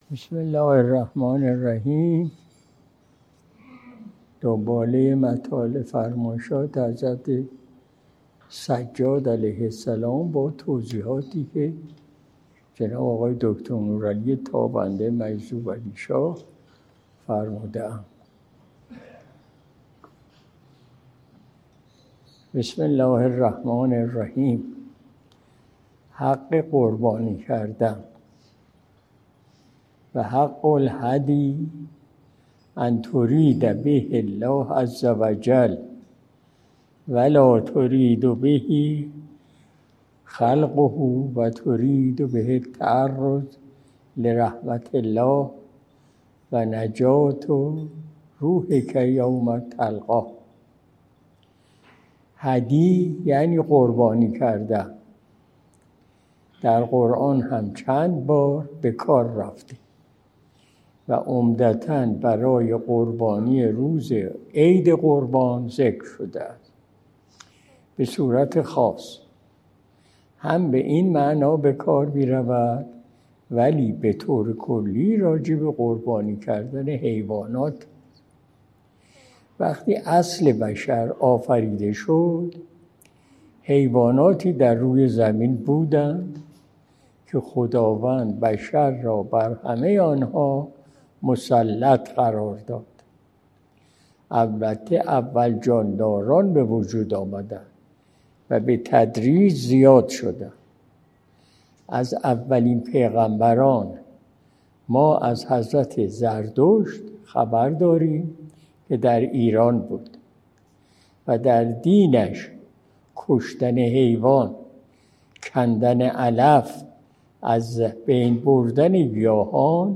مجلس شب جمعه ۱۲ مرداد ماه ۱۴۰۲ شمسی